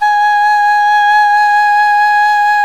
SULING VIB04.wav